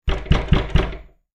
Звуки стуков в дверь
Дверь трясется от громкого стука